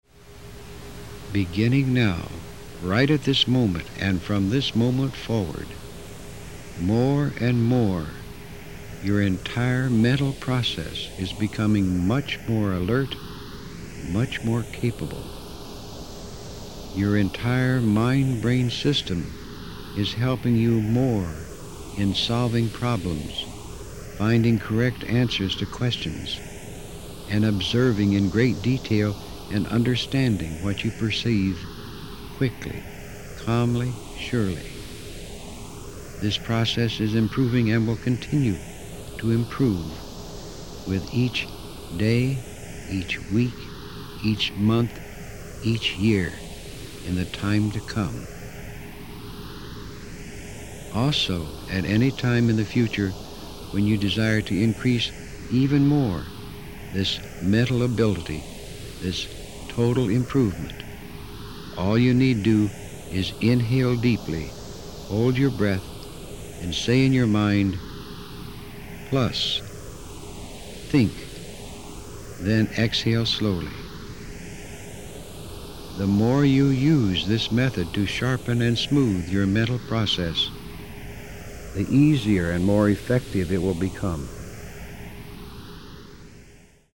Verbální vedení: Anglické verbální vedení